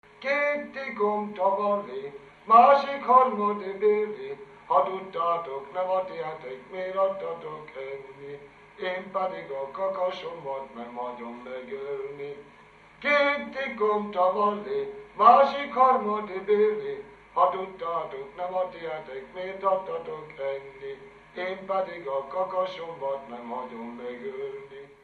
Dunántúl - Zala vm. - Nova
Stílus: 7. Régies kisambitusú dallamok